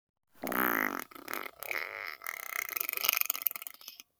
Play, download and share Fart teheheheee original sound button!!!!
fart-teheheheee.mp3